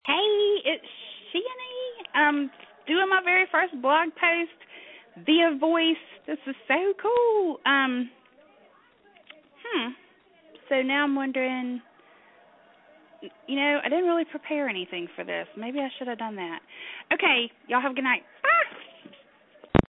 Living out loud in the Carolinas
adore your accent!